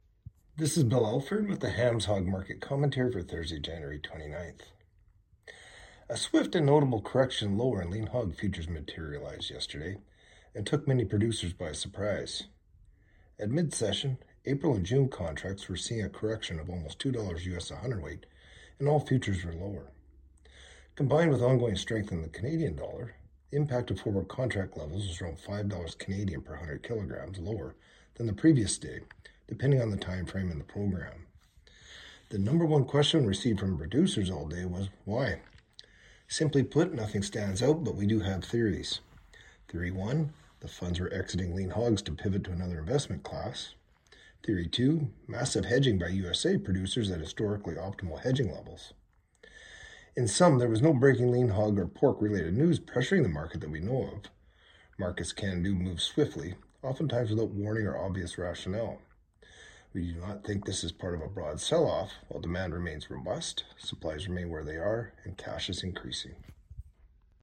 Hog-Market-Commentary-Jan.-29-26.mp3